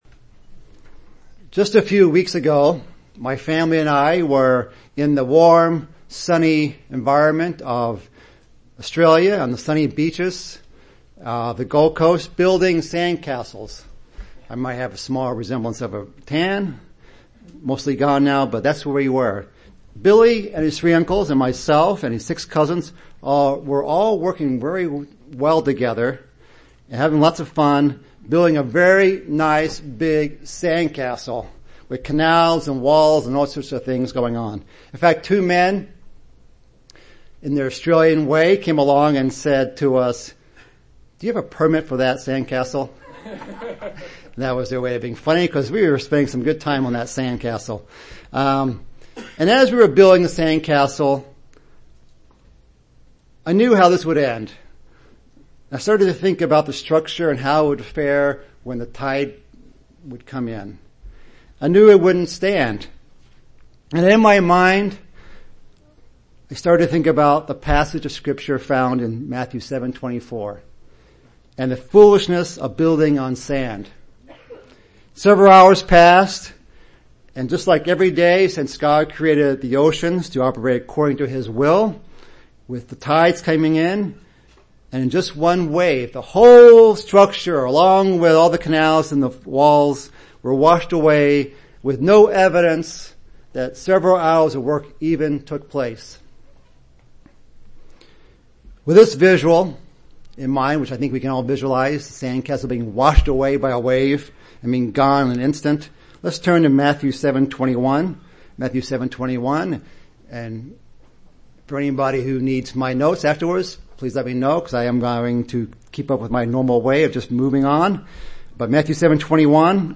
This sermon reviews three principles of building on the Rock including know who the Rock is, listening to Christ's words, and doing Christ's words, as well as a review of the teachings of Christ that we need to be doing covered in the Sermon on the Mount.
Given in Chicago, IL